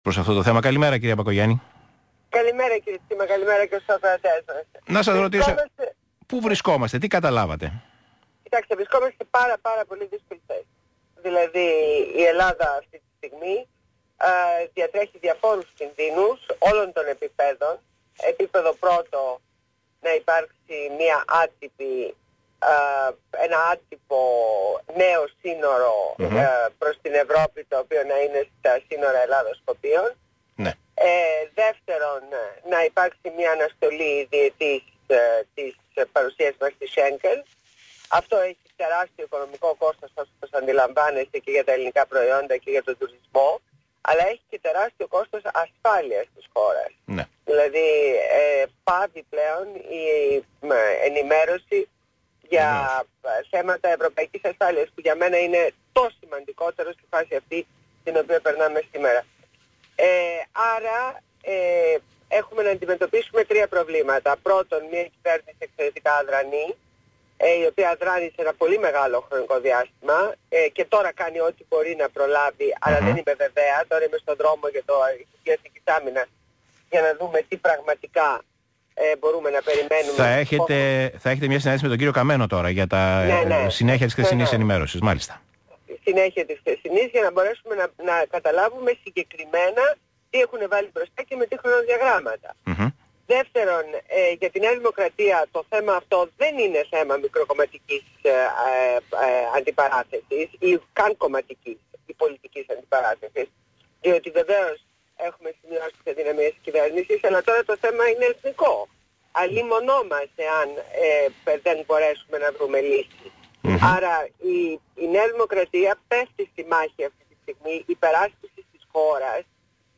Συνέντευξη στο ραδιόφωνο του ΣΚΑΙ, στην εκπομπή του Π. Τσίμα.